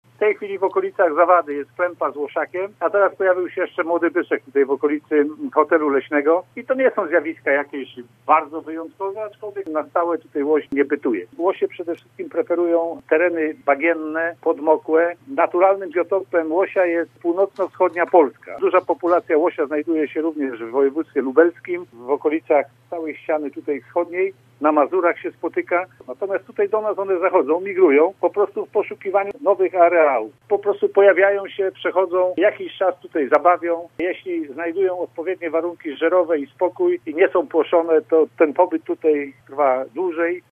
w rozmowie z Radiem Zielona Góra